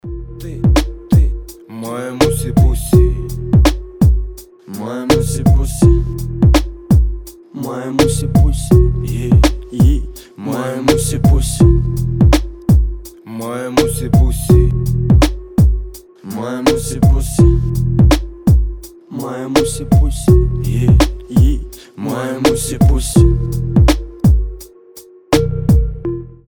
русский рэп
нецензурная лексика